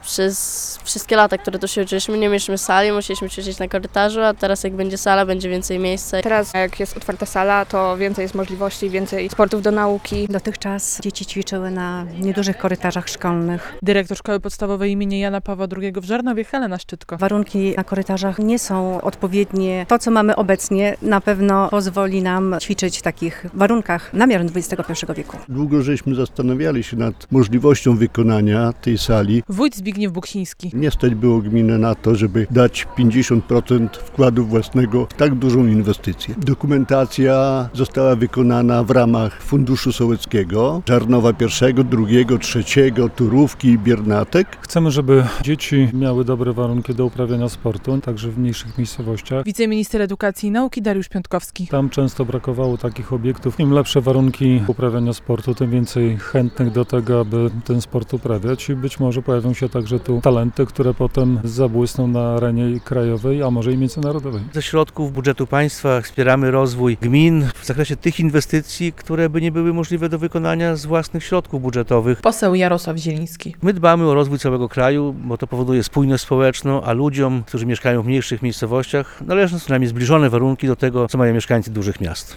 W Żarnowie otwarto nową salę gimnastyczną - relacja